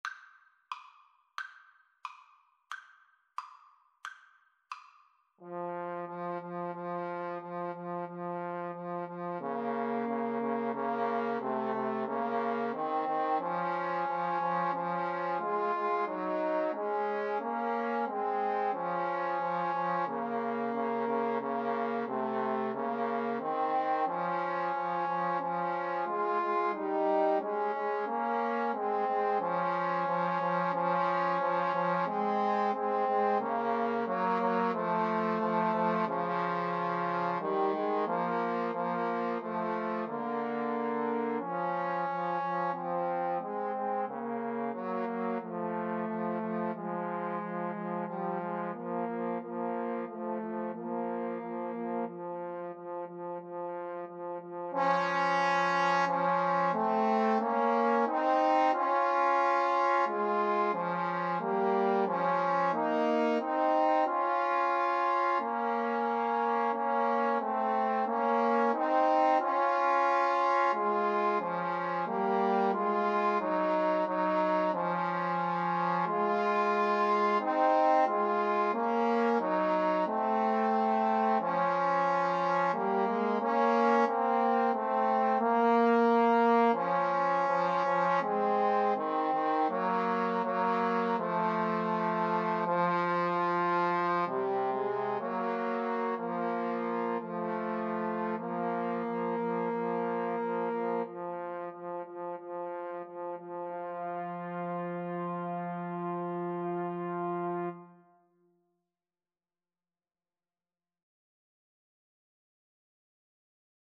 =90 Allegretto, ma un poco lento
2/4 (View more 2/4 Music)
Trombone Trio  (View more Easy Trombone Trio Music)
Classical (View more Classical Trombone Trio Music)